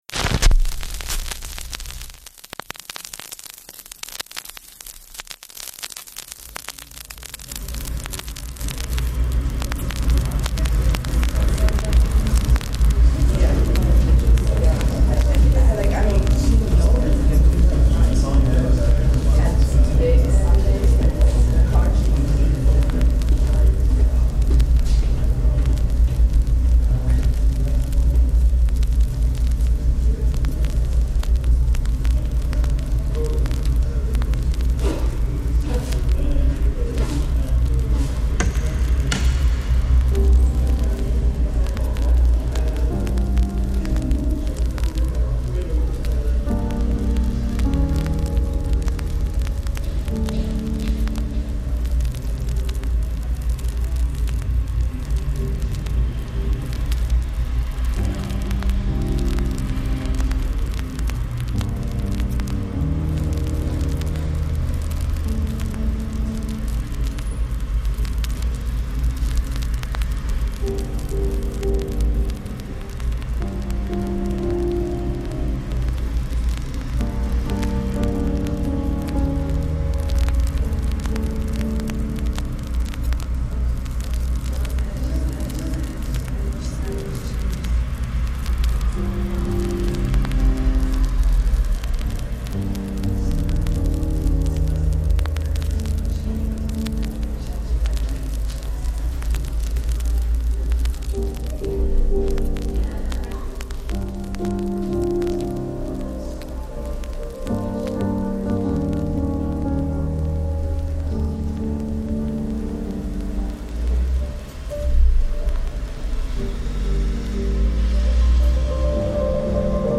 Boros Collection soundscape, Berlin reimagined